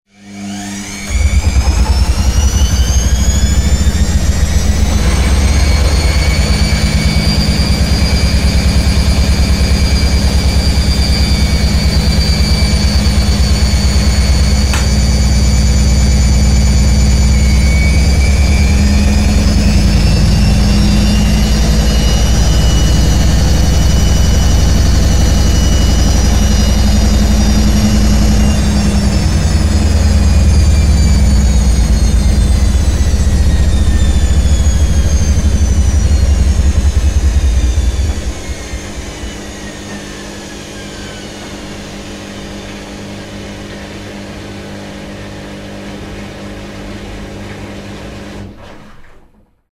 Centrifugado de una lavadora
lavadora
Sonidos: Hogar